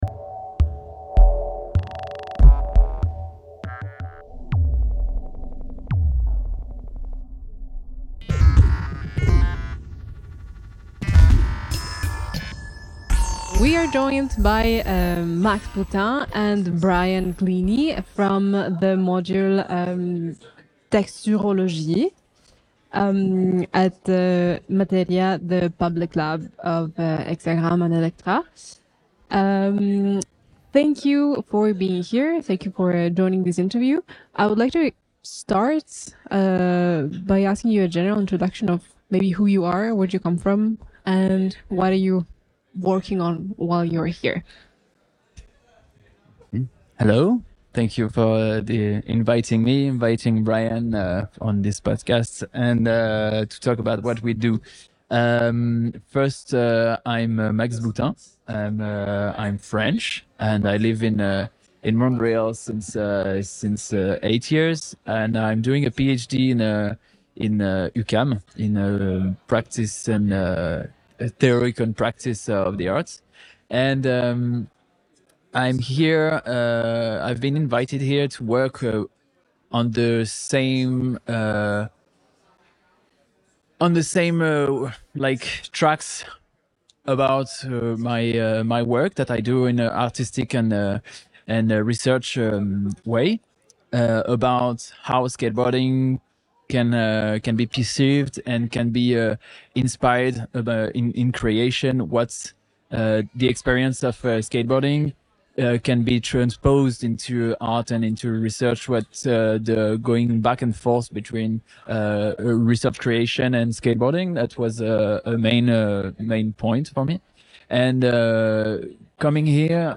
Des artistes, chercheurs et skateurs se réunissent autour de la table pour explorer ce concept et le porter vers de nouvelles avenues, à l’intersection entre la création en art numérique et la pratique du skateboard.